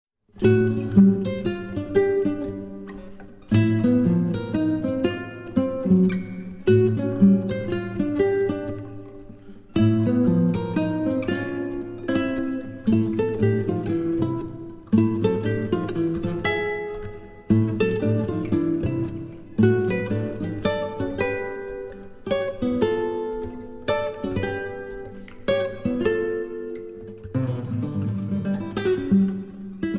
Shimmering Guitar and Lute Duets Composed and Improvised
a more angular, rhythmic approach